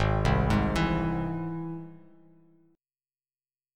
Ab+ Chord
Listen to Ab+ strummed